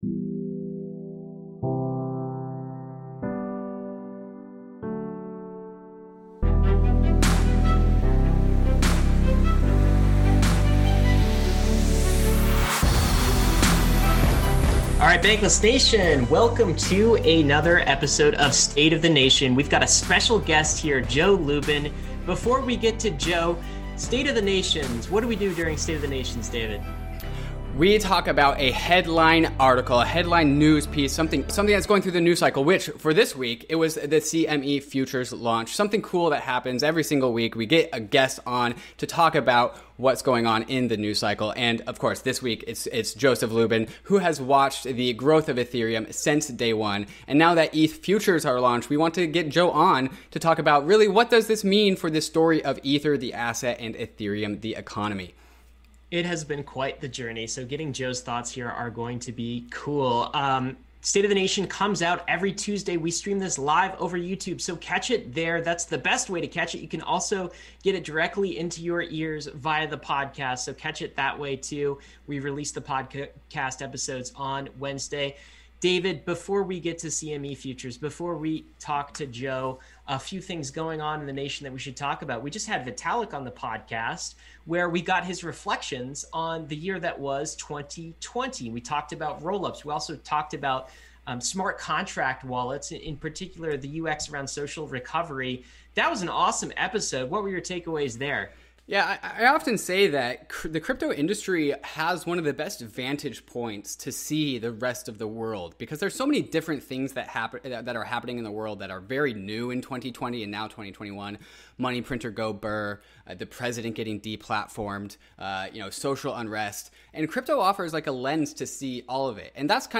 Guest Joseph Lubin